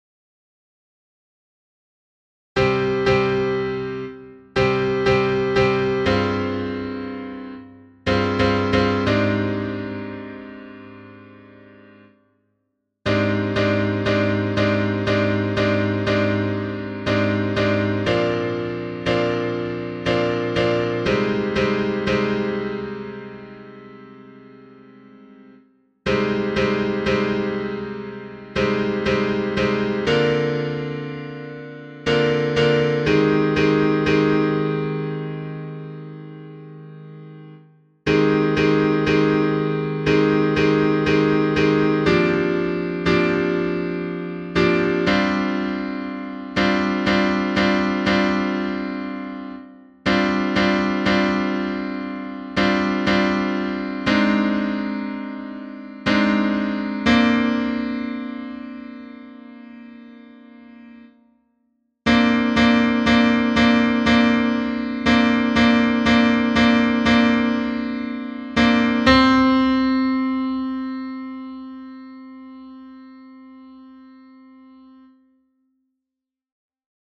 for us to make practice tracks.)
Tutti
The featured part is a horn or a bassoon;
other parts are a piano sound.